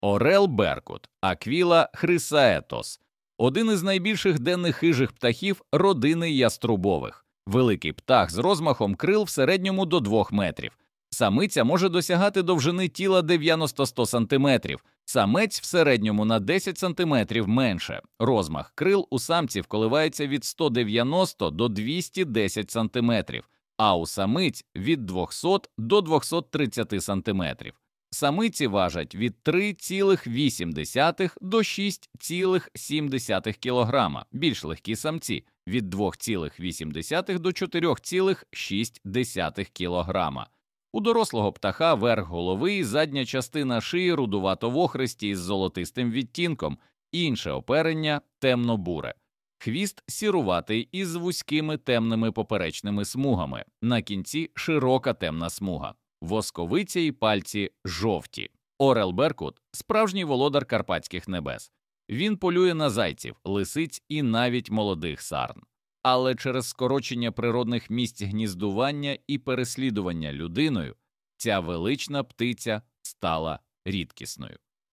Орел-беркут
Звук птаха